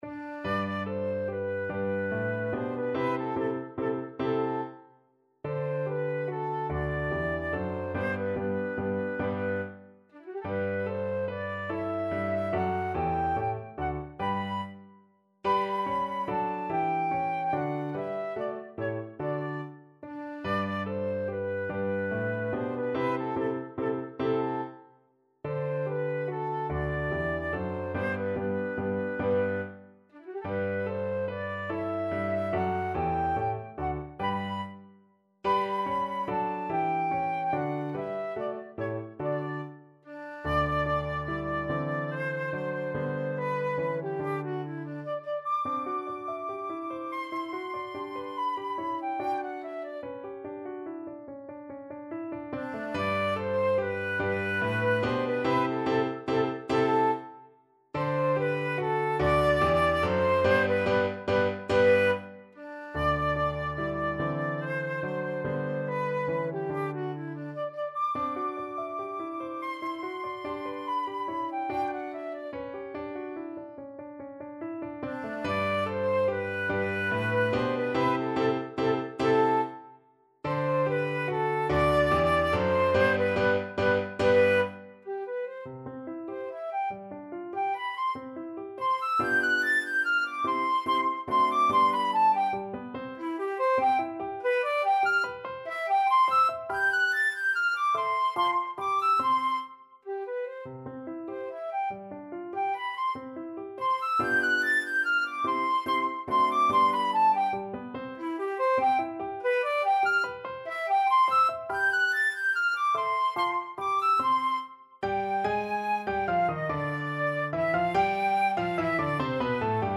3/4 (View more 3/4 Music)
Moderato =c.144
Classical (View more Classical Flute Music)